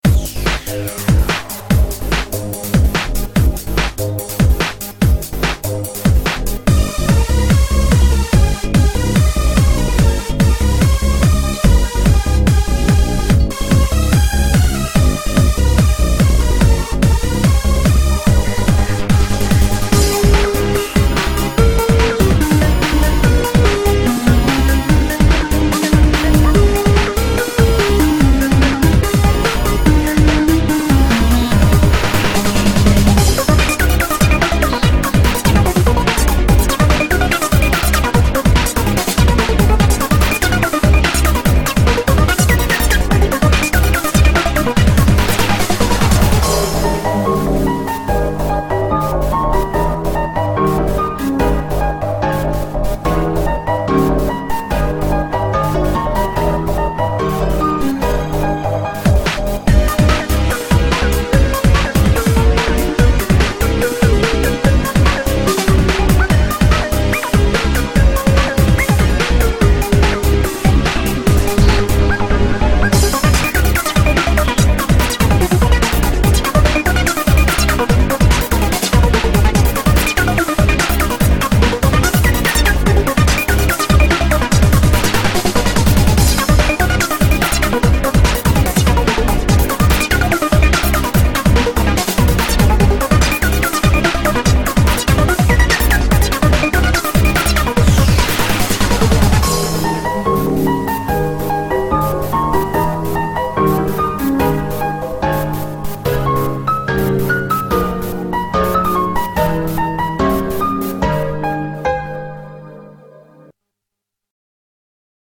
BPM73-145
Audio QualityPerfect (High Quality)
Comments[VG-REMIX]